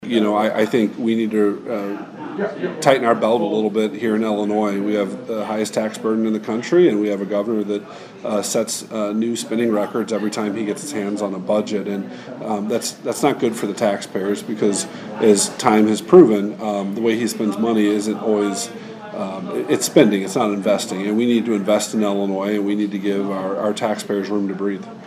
State Senator Jason Plummer was in Vandalia on Wednesday to address the monthly meeting of the Greater Fayette County Chamber of Commerce.